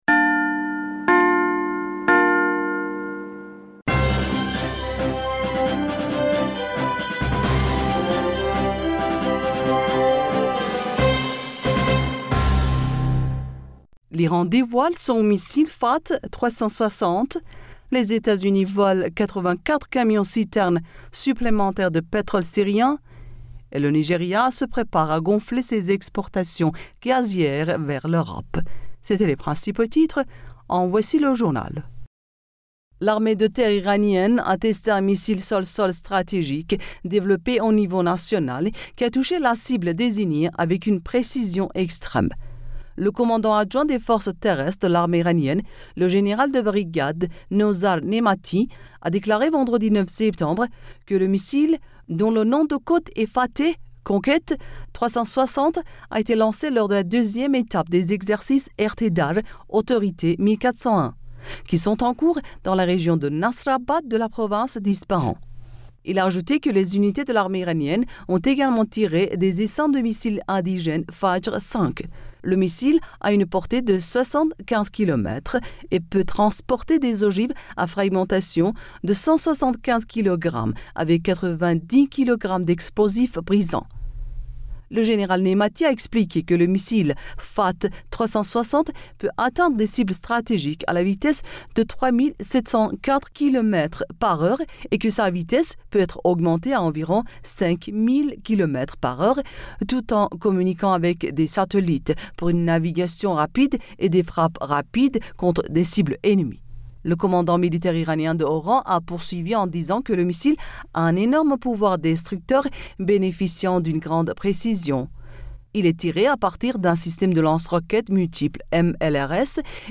Bulletin d'information Du 10 Septembre